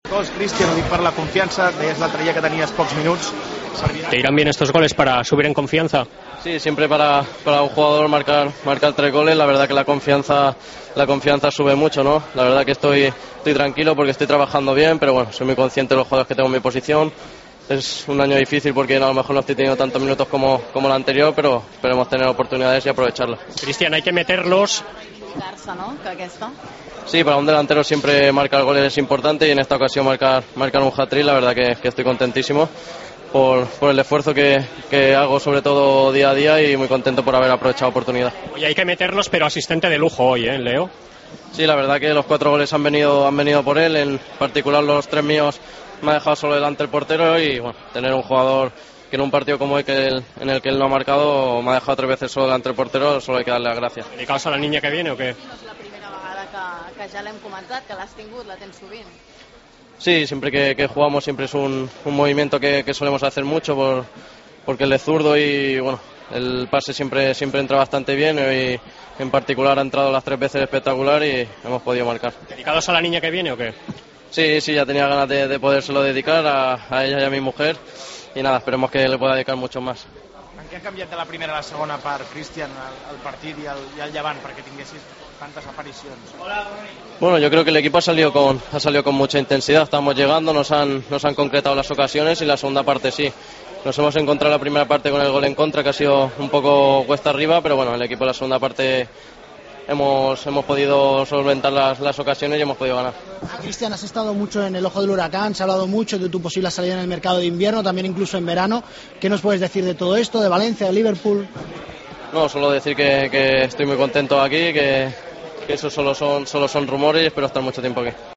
Escuchamos a Cristian Tello, protagonista del Levante-Barcelona de Copa: "Para un jugador, siempre marcar tres goles, la confianza sube mucho.